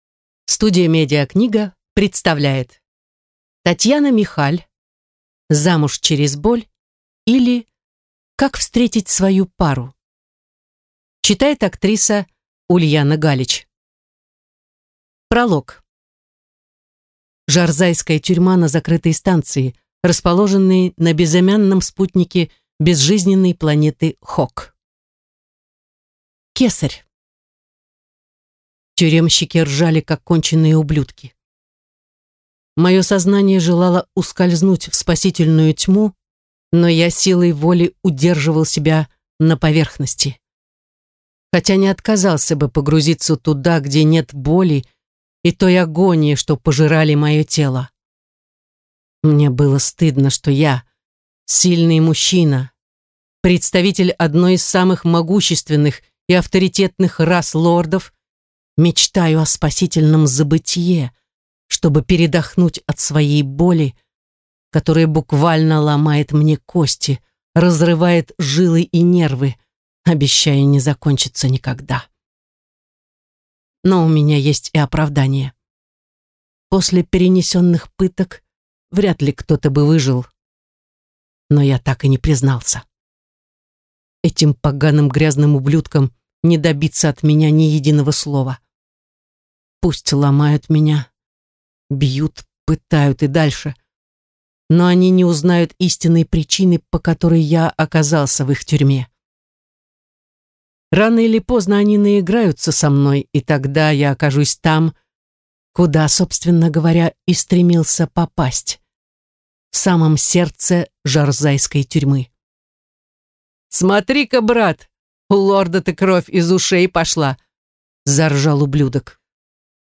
Аудиокнига Замуж через боль, или Как встретить свою пару | Библиотека аудиокниг